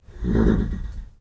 Horse Nicker Sound Effect Free Download
Horse Nicker